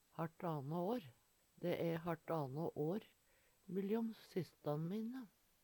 Sjå òg mylljom (Veggli) Høyr på uttala Ordklasse: Uttrykk Kategori: Tal, mål, vekt Uttrykk Attende til søk